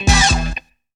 MORSE CODE.wav